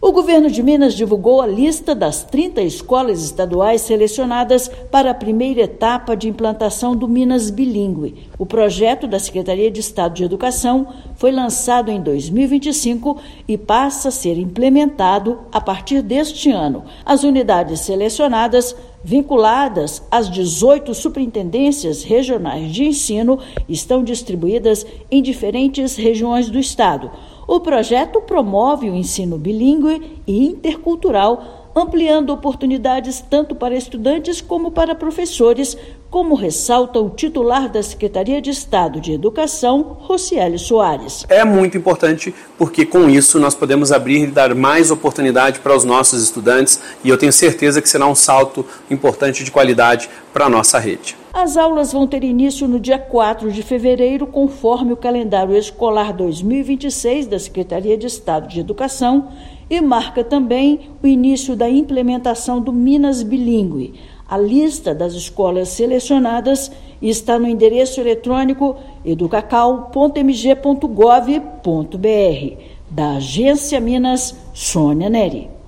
Projeto amplia ensino bilíngue na rede estadual e fortalece oportunidades acadêmicas e profissionais. Ouça matéria de rádio.